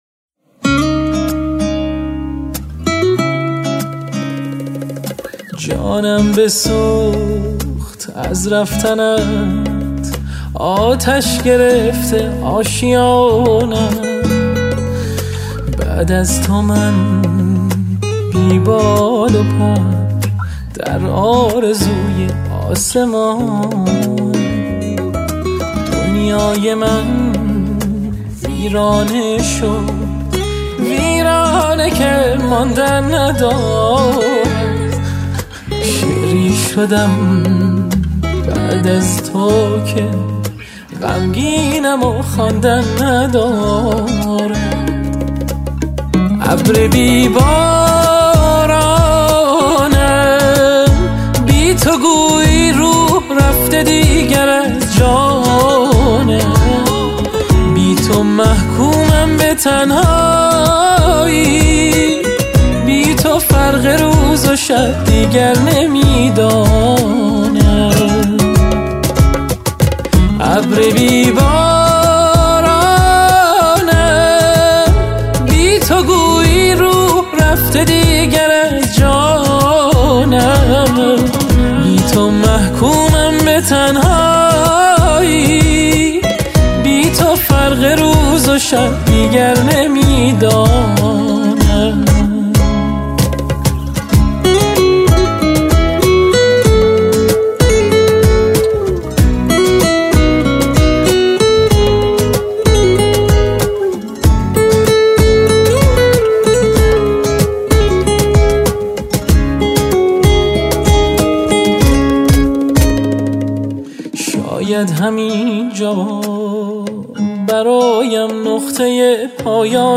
بک وکال